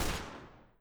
pistol_shoot.wav